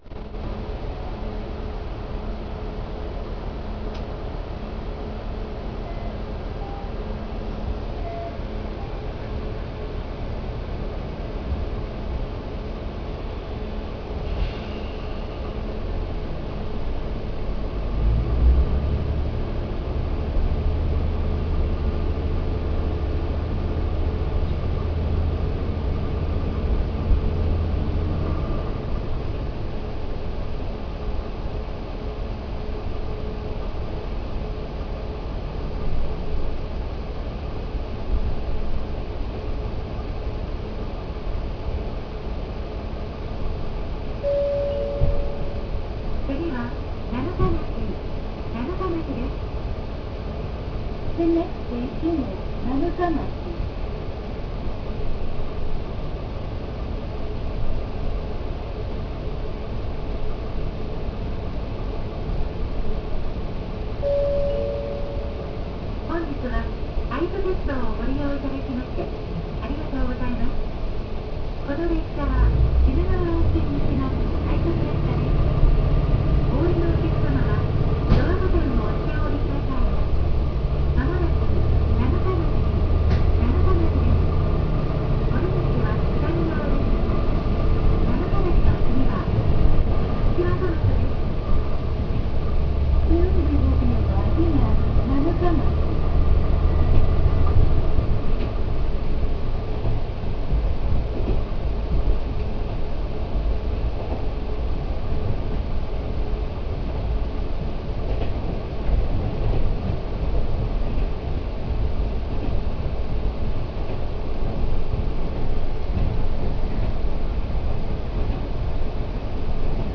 ・AT-700型走行音
【JR只見線】会津若松→七日町（2分53秒：945KB）
カミンズ製直列横型6気筒N14Rディーゼルエンジンを搭載している…とのこと（wikipediaより）。走行音自体はそんなに賑やかなものではありません。ドアチャイムはJR西タイプ。
全然速度出てません。